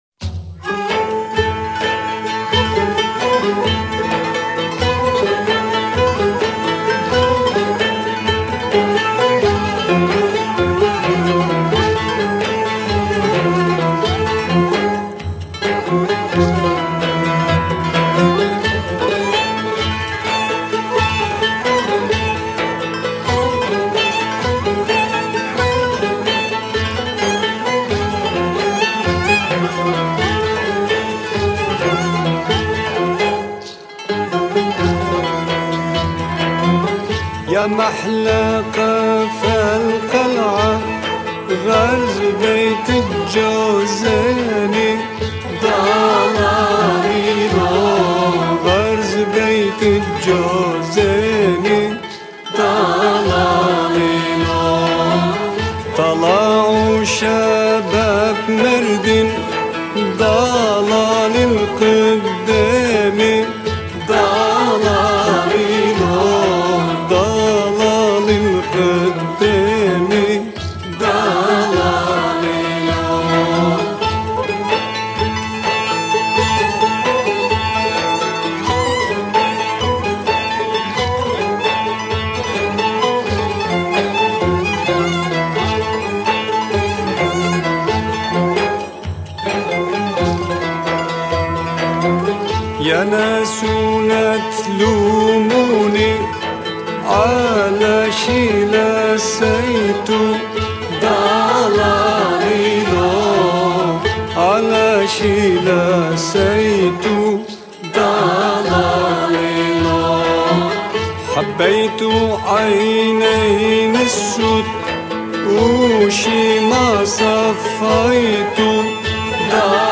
Etiketler: türkiye, türkü